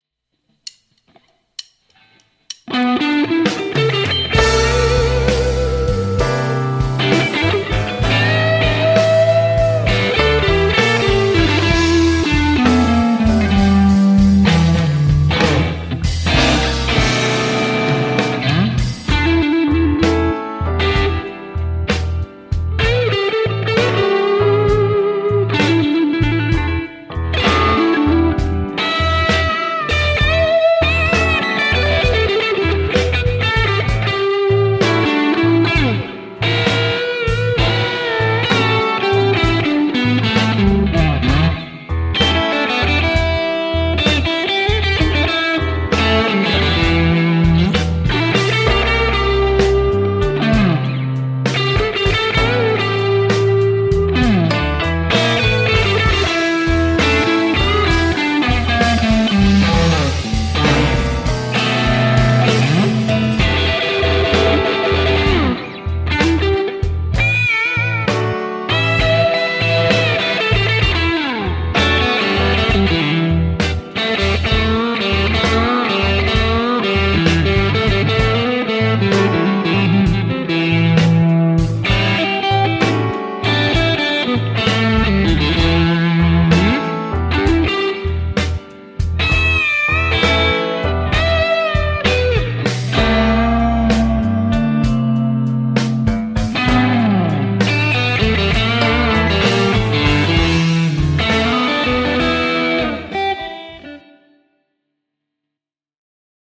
Tausta vaihteeksi mollimpaa shufflea..